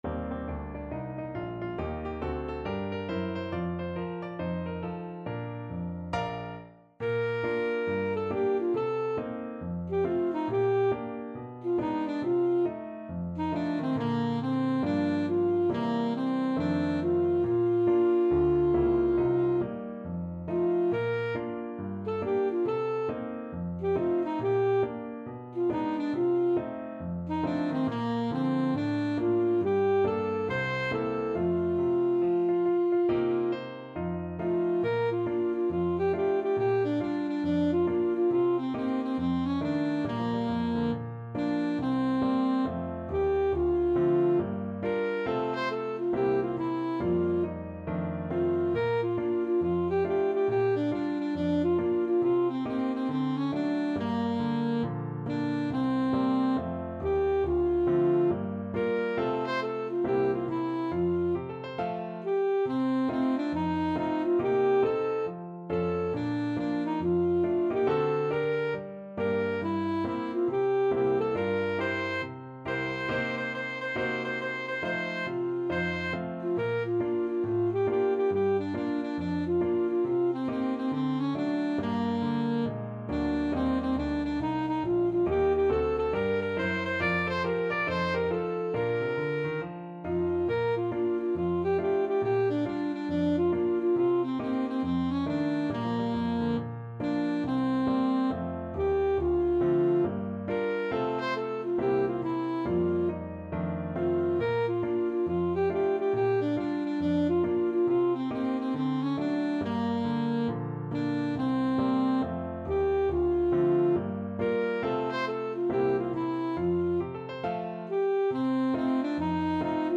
Alto Saxophone
4/4 (View more 4/4 Music)
With a swing =c.69
Pop (View more Pop Saxophone Music)